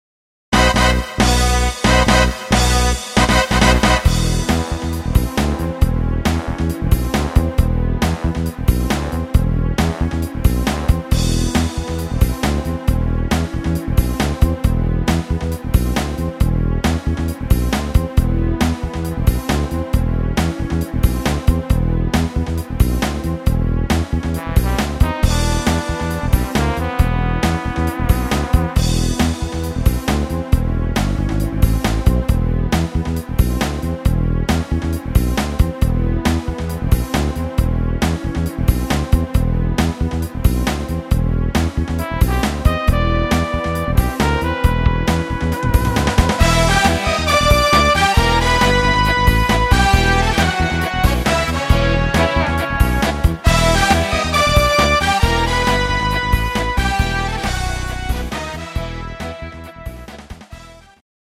Playback abmischen  Playbacks selbst abmischen!
instr. Gitarre